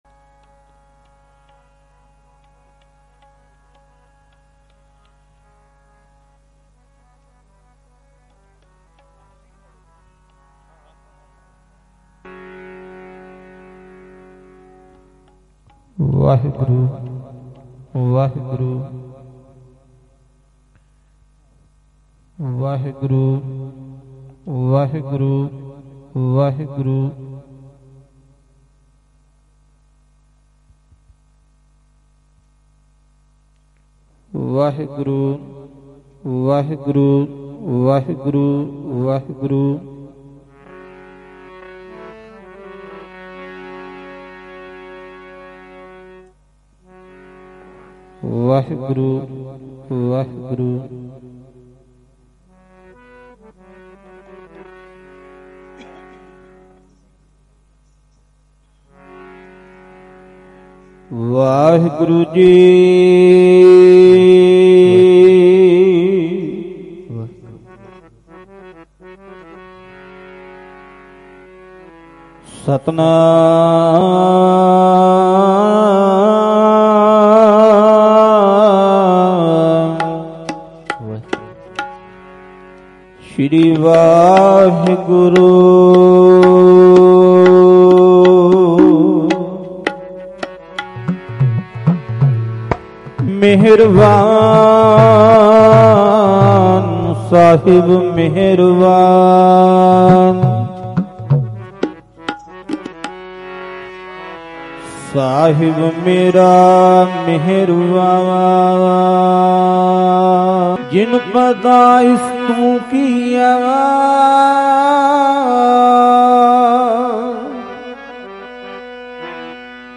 Live gurmat samagam Bhilai Chhattisgarh 16 jan 2026